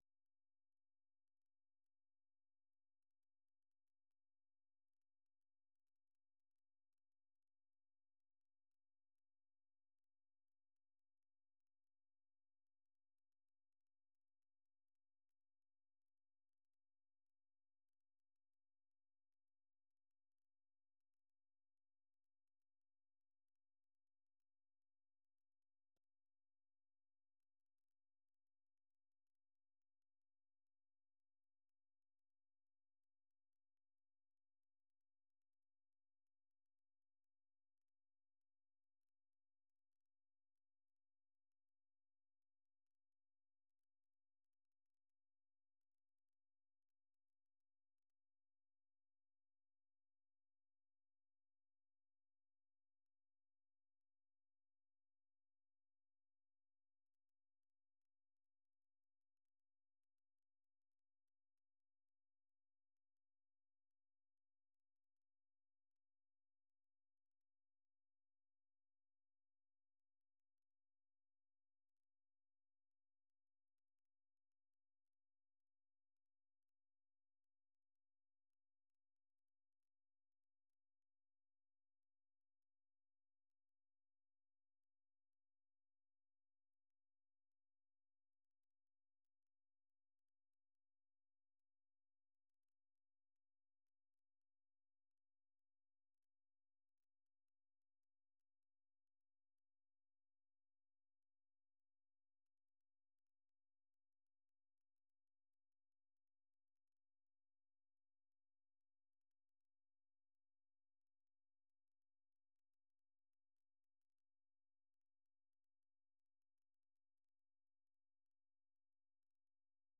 ፈነወ ትግርኛ ብናይ`ዚ መዓልቲ ዓበይቲ ዜና ይጅምር ። ካብ ኤርትራን ኢትዮጵያን ዝረኽቦም ቃለ-መጠይቓትን ሰሙናዊ መደባትን ድማ የስዕብ ። ሰሙናዊ መደባት ሰኑይ፡ ሳይንስን ተክኖሎጂን / ሕርሻ